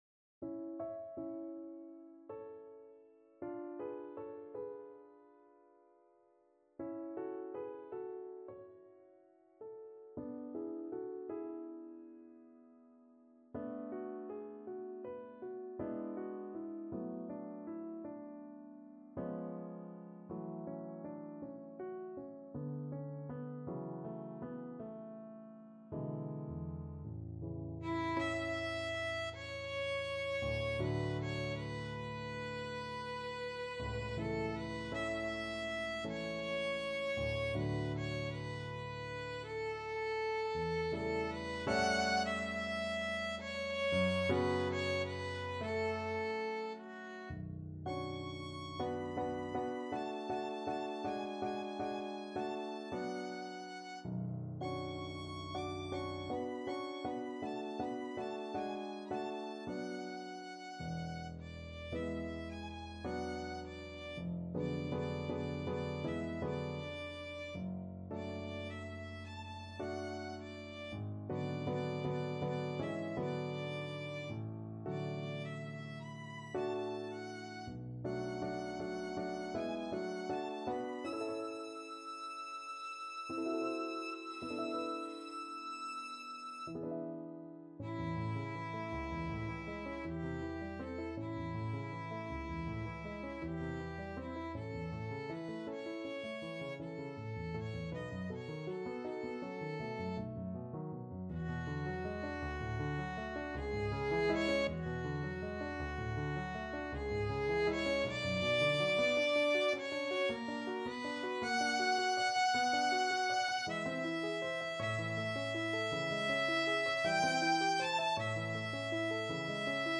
Classical Debussy, Claude Clair De Lune Violin version
ViolinPiano
A major (Sounding Pitch) (View more A major Music for Violin )
9/8 (View more 9/8 Music)
Andante tres expressif
Classical (View more Classical Violin Music)